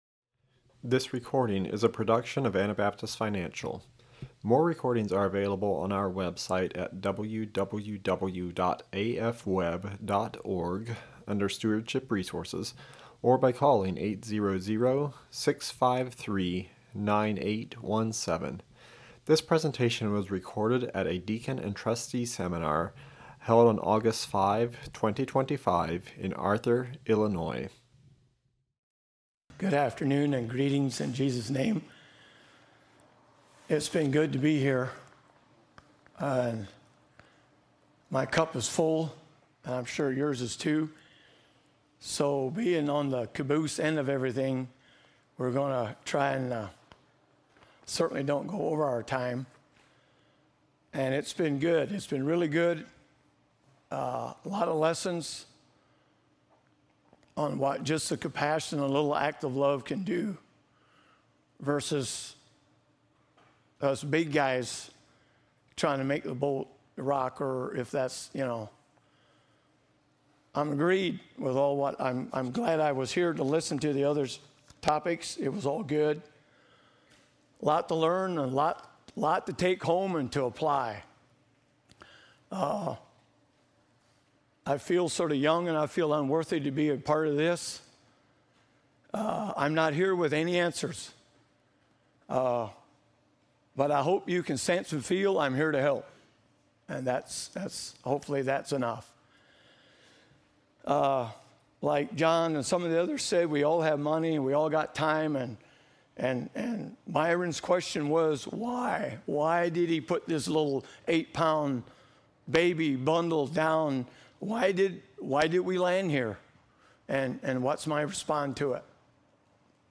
This is a recording from the Seminar for Deacons, Financial Advisors, and Trustees held in Arthur, IL in 2025.